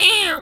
bird_tweety_hurt_02.wav